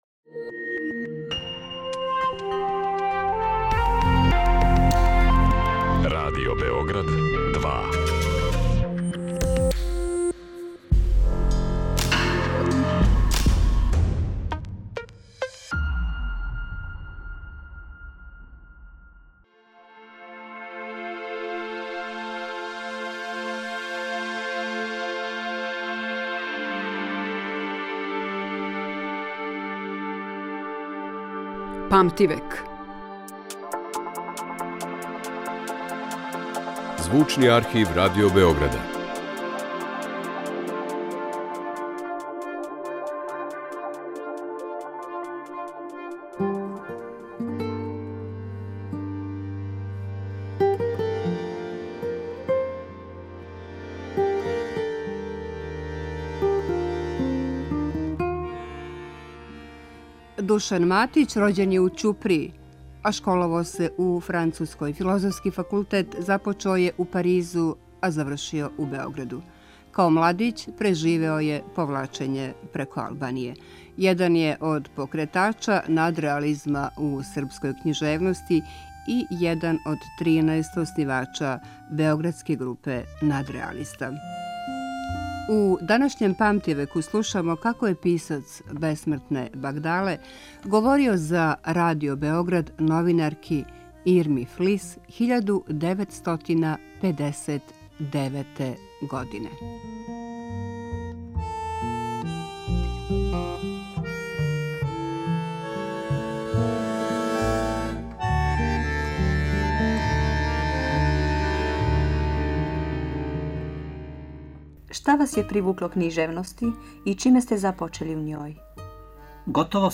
У данашњем Памтивеку слушаћемо како је писац бесмртне Багдале говорио за Радио Београд.